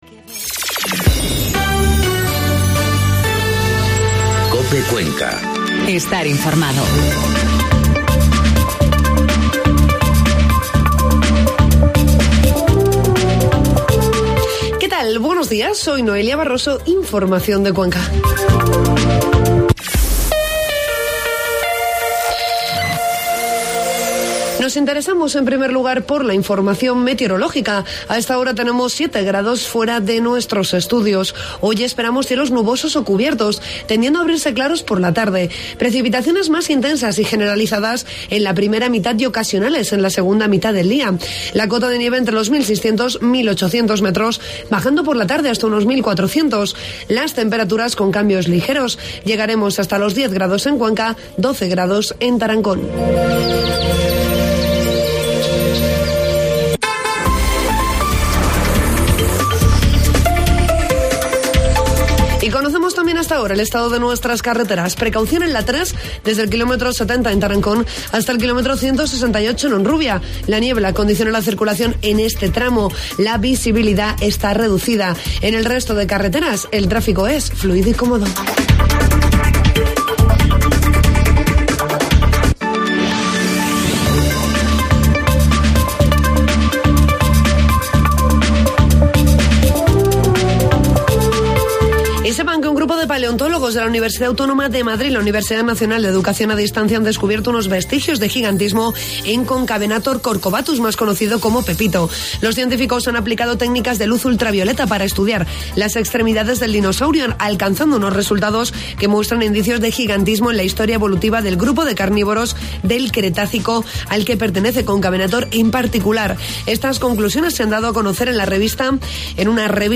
Informativo matinal COPE Cuenca 20 de noviembre